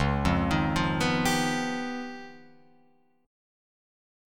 C#9b5 chord